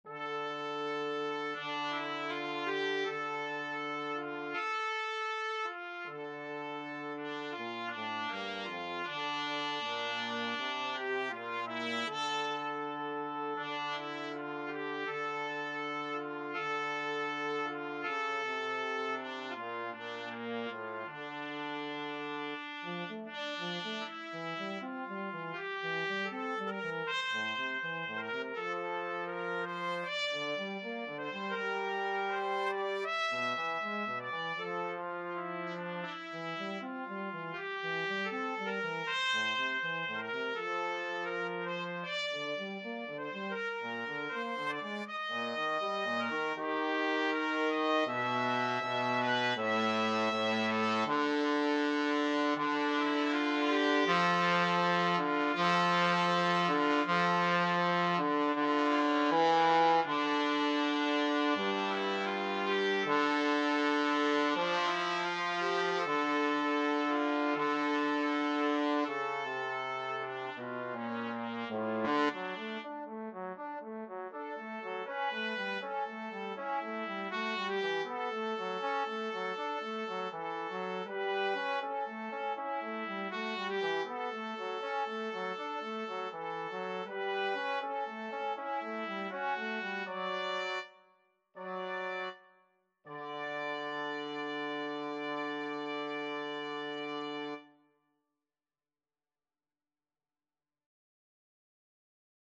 4/4 (View more 4/4 Music)
Andante = 80
Classical (View more Classical Trumpet-Trombone Duet Music)